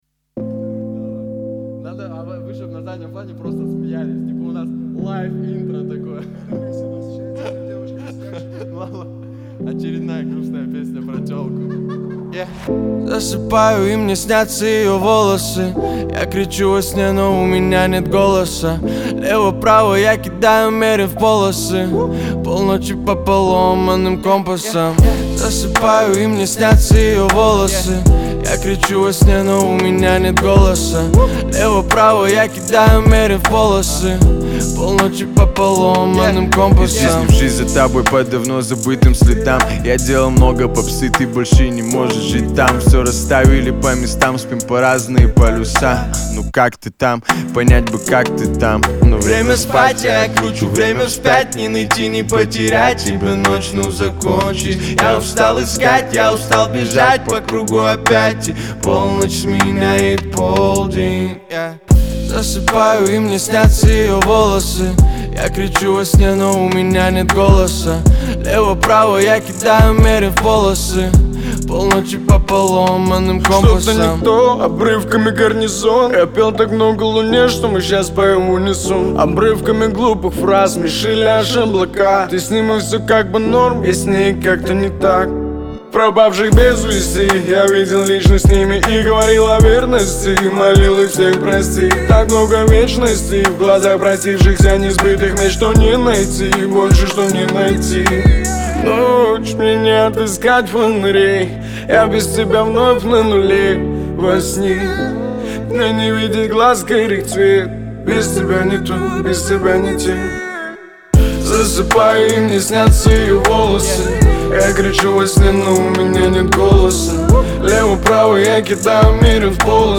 Жанр: rusrap
Rap, Hip-Hop